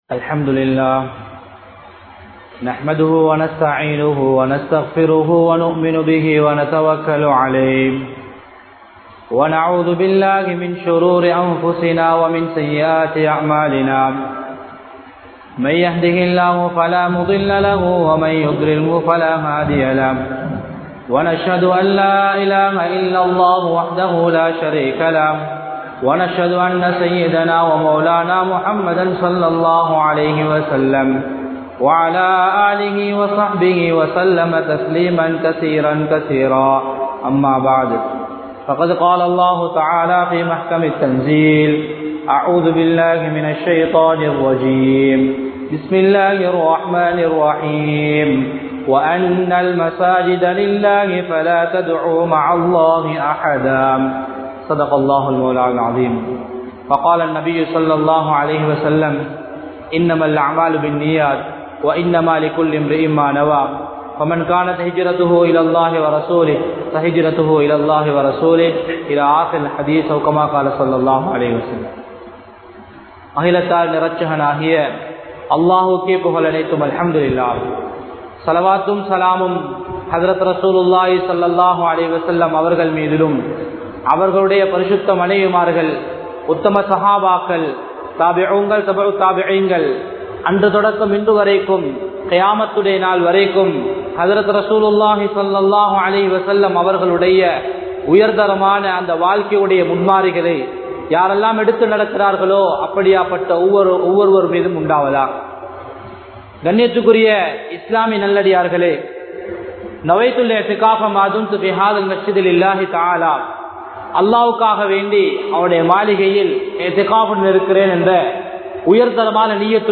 Masjidhhalin Punitham Peanuvoam (மஸ்ஜித்களின் புனிதம் பேணுவோம்) | Audio Bayans | All Ceylon Muslim Youth Community | Addalaichenai